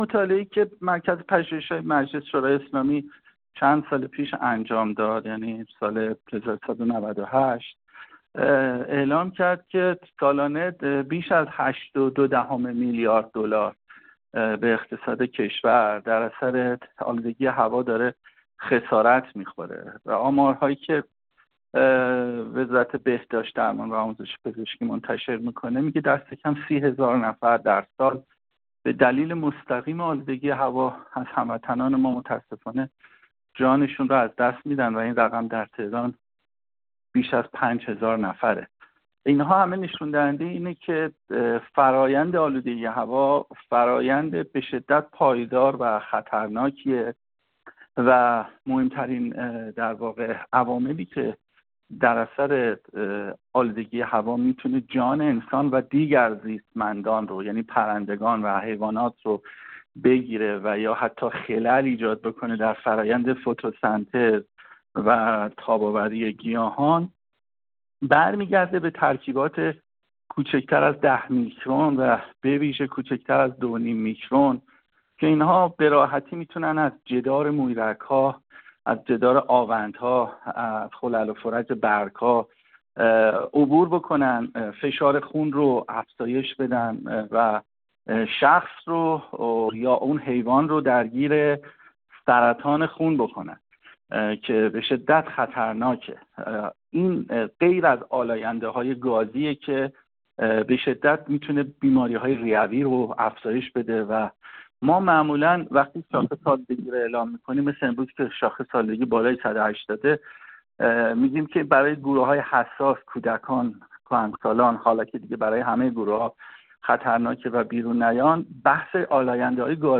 در همین خصوص محمد درویش، کنشگر محیط زیست، پژوهشگر و کویرشناس در گفت‌وگو با ایکنا به تشریح تأثیرات آلودگی هوا بر زندگی و مرگ مردم پرداخت و گفت: مطالعه‌ مرکز پژوهش‌های مجلس در سال 98 نشان می‌دهد آلودگی هوا سالانه بیش از 8.2 میلیارد دلار به اقتصاد کشور خسارت وارد می‌کند.